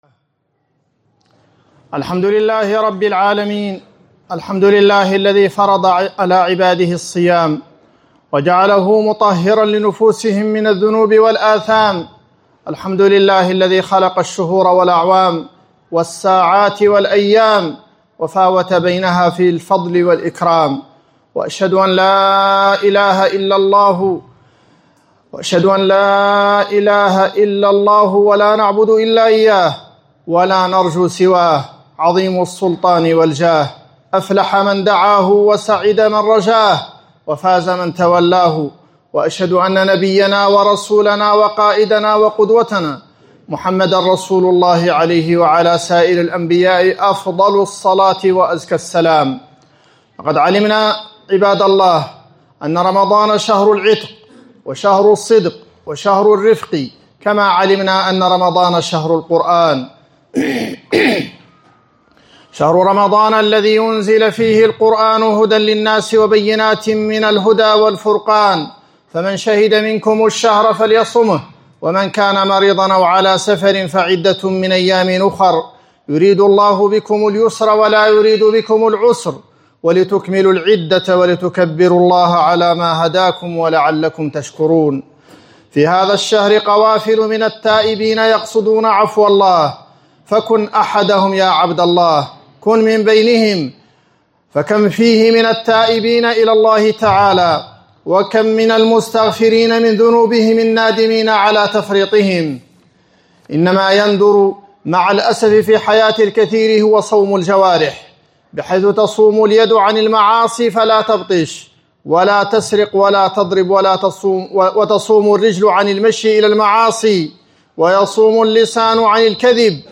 خطبة في فضل العمل الصالح في رمضان